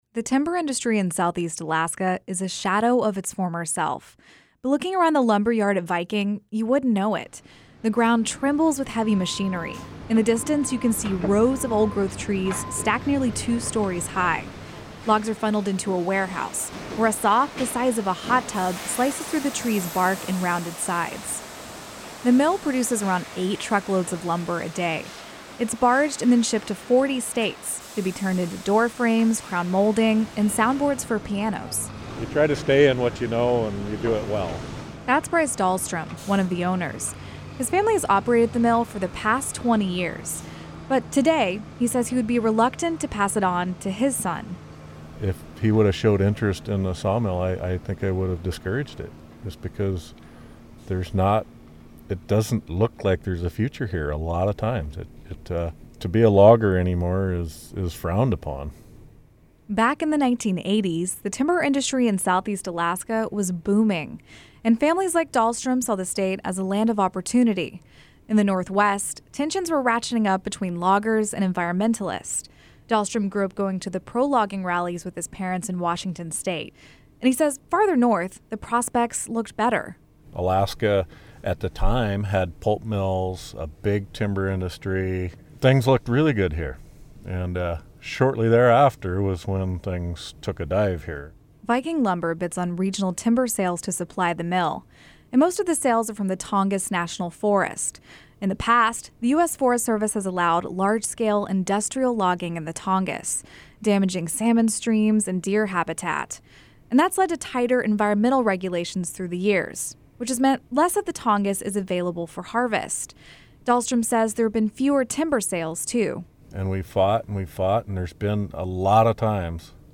The ground trembles with heavy machinery.
Logs are funneled into a warehouse, where a saw the size of a hot tub slices through the tree’s bark and rounded sides.